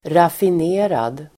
Uttal: [rafin'e:rad]